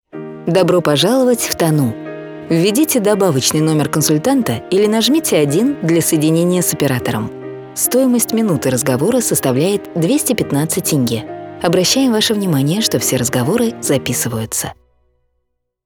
IVR